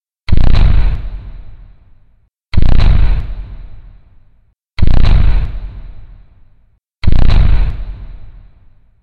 针对音效空心木硬鞋行走的PPT演示模板_风云办公